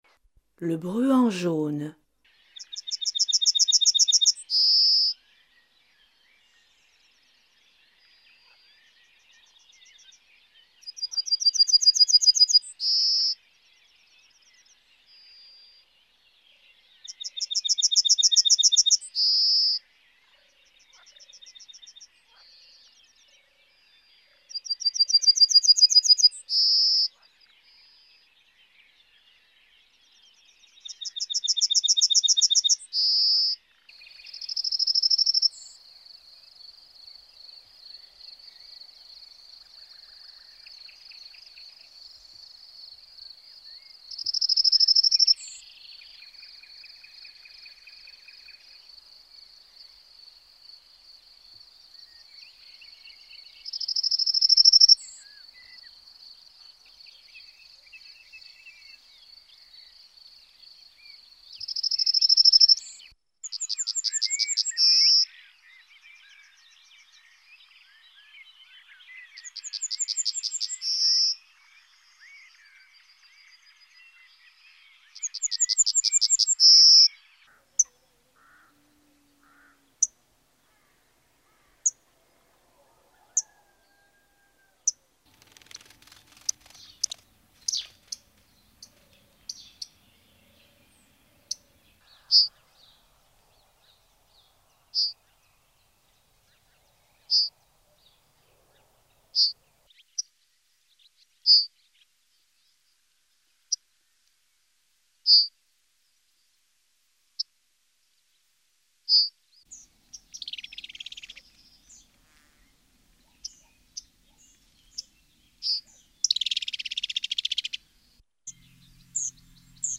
oiseau
bruantjaune.mp3